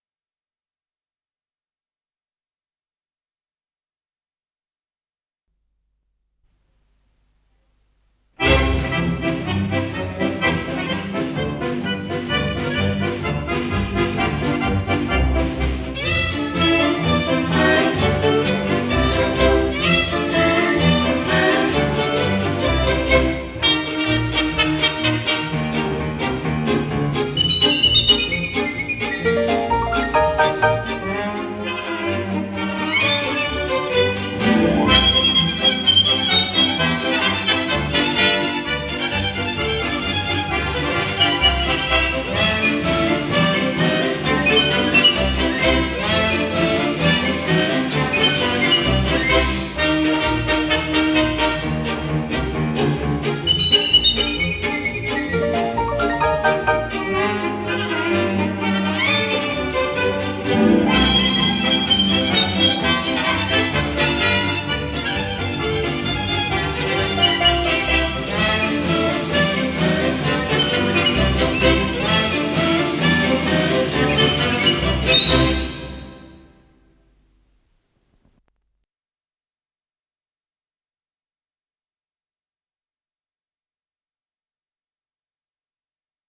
Полька